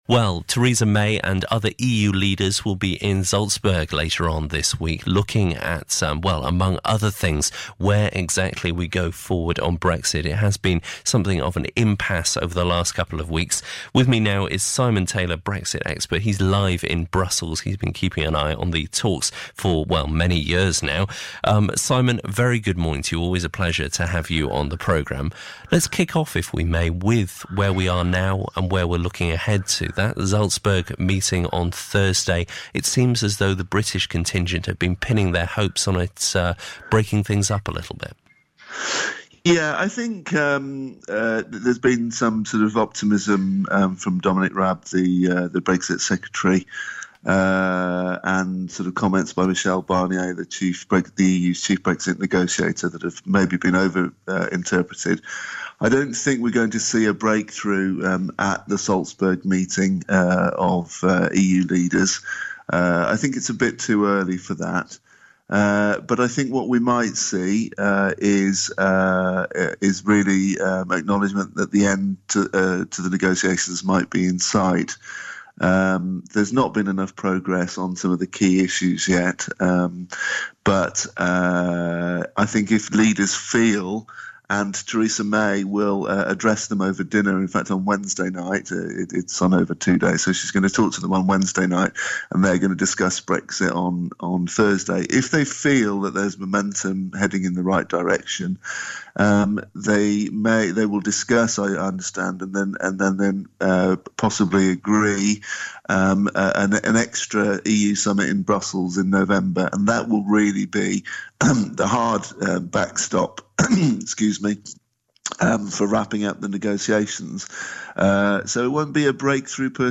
live from Brussels, Jazz FM Business Breakfast